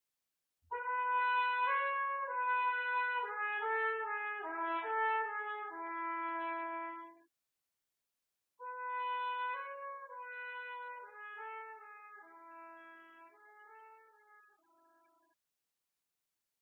描述：这首歌是MPB风格，由小号独奏引出。 为钢琴、声乐、小号和长号制作的
标签： 原声
声道立体声